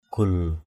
/ɡ͡ɣul/ 1.